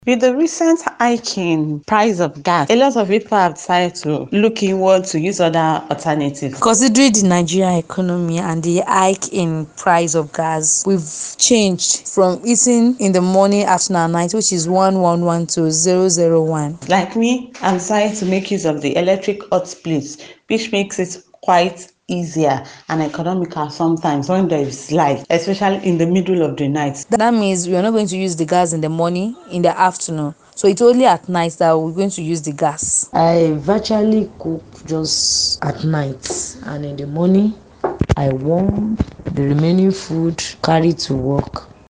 Residents of Ibadan shared their struggles and alternative cooking methods amidst the soaring prices during an interview